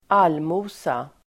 Uttal: [²'al:mo:sa]